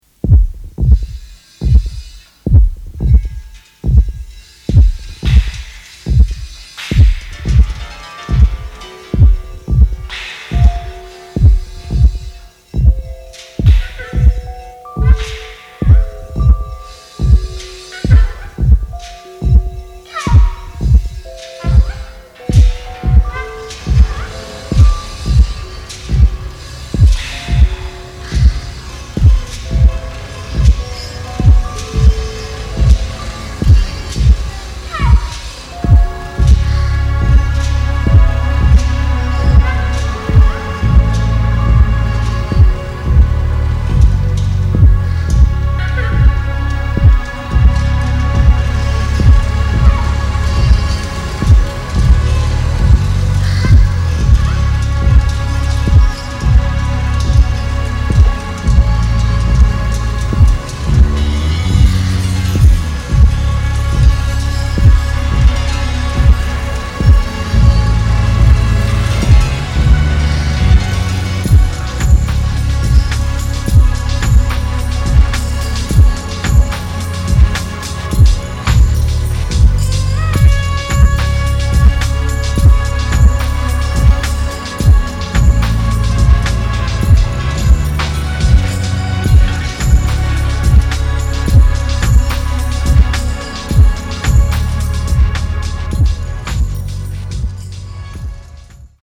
キーワード：ミニマル　サウンドスケープ　空想民俗